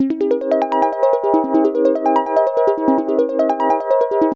Index of /90_sSampleCDs/AMG - Global Trance Mission VOL-1/Partition A/Analog SEQ 1
JUP8HARP D#M.wav